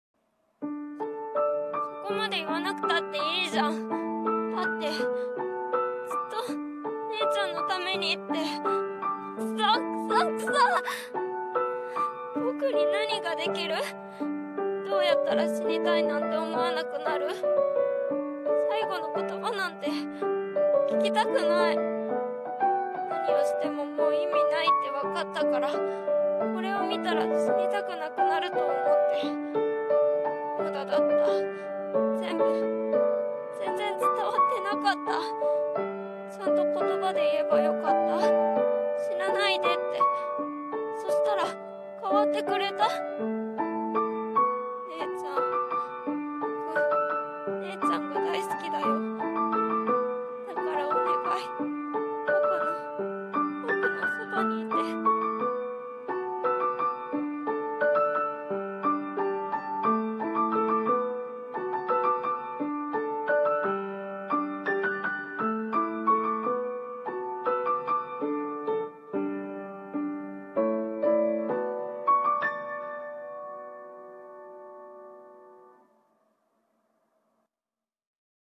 【ショタボ】
【声劇台本】 / 「聲の形」 西宮結絃 より(引用&付け加え)